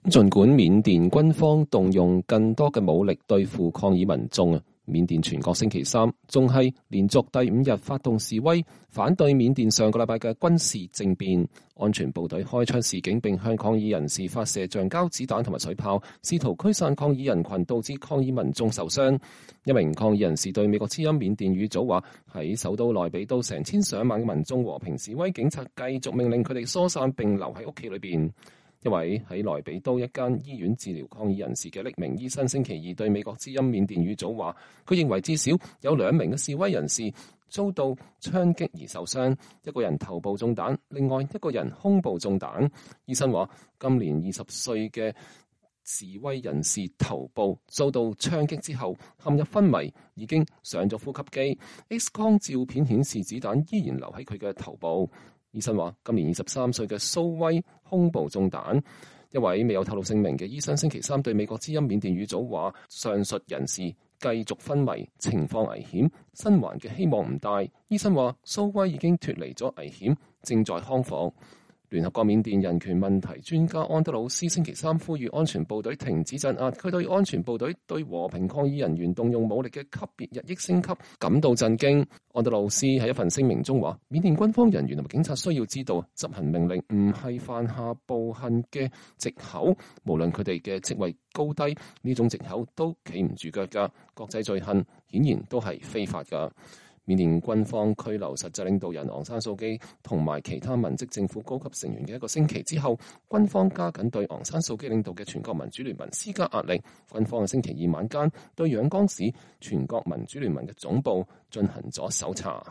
美國總統拜登2月10日就緬甸問題發表講話。